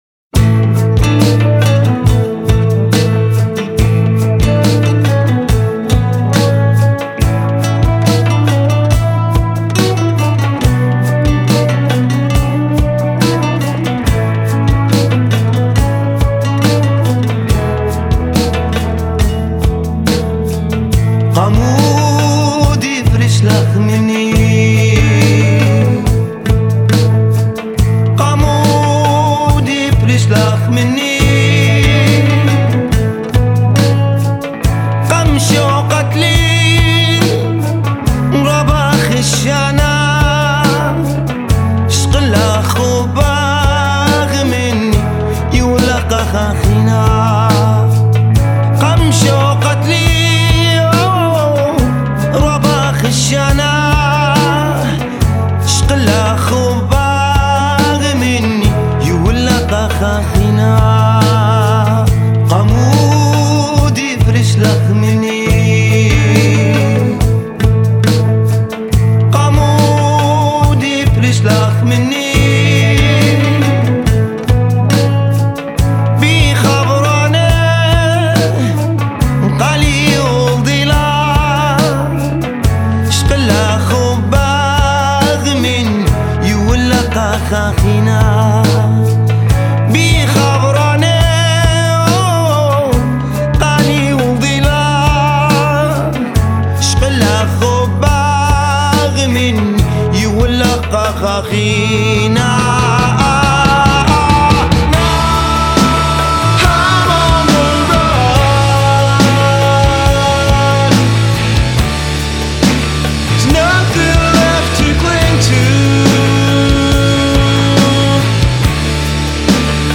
world-fusion group
dance-driven style